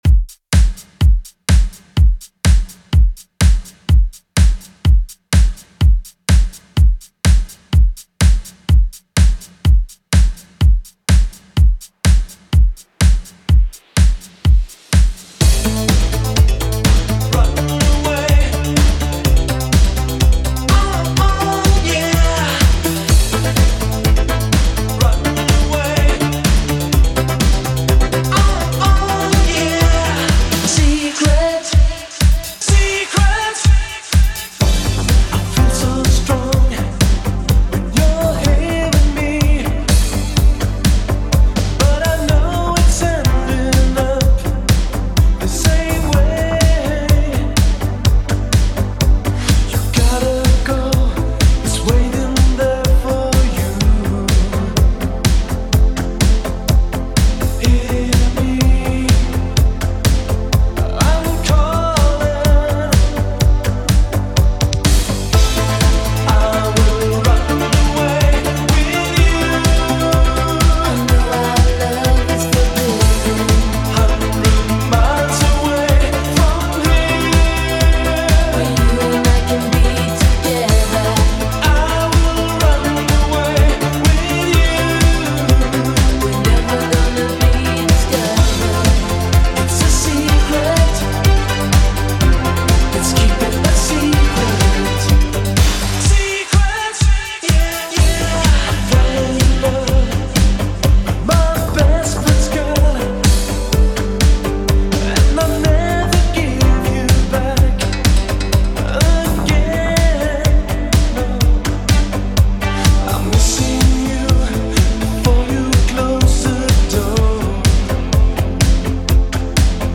Genre: 90's Version: Clean BPM: 98